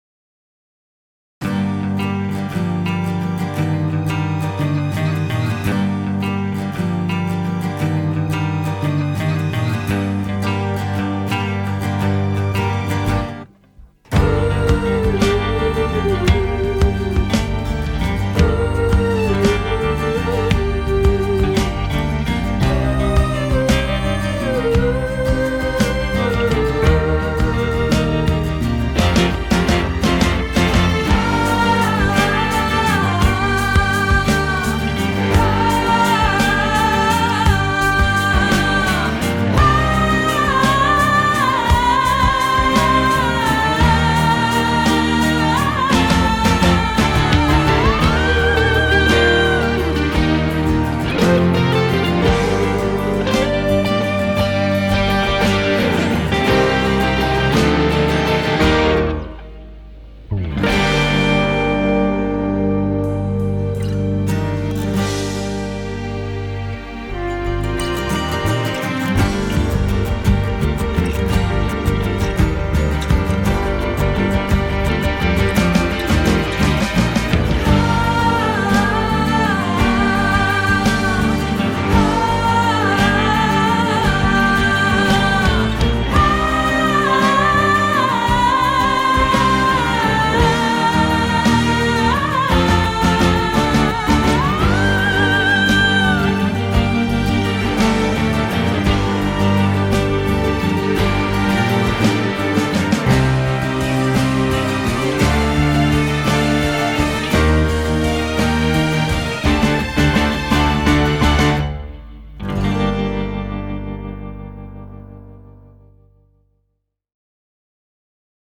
tema dizi müziği, duygusal hüzünlü rahatlatıcı fon müziği.